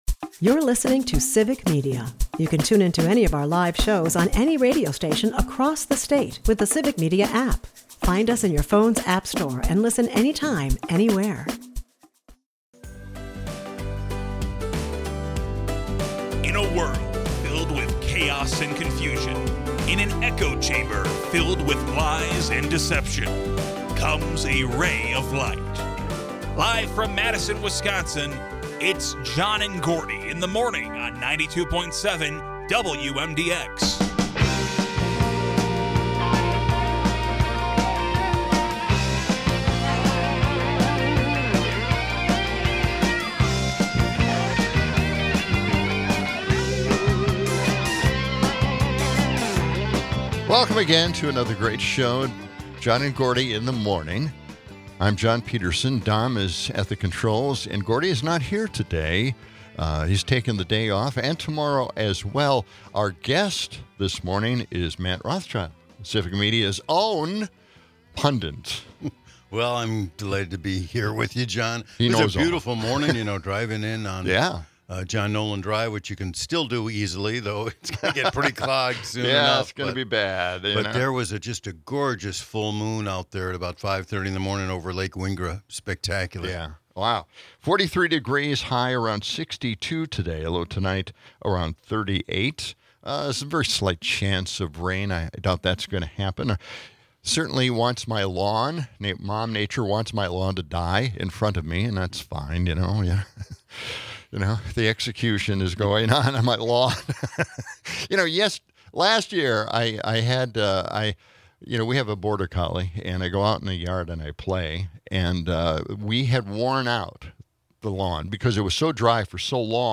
Callers add perspectives on the effectiveness of protests, prompting a lively debate on activism's role in today's political climate.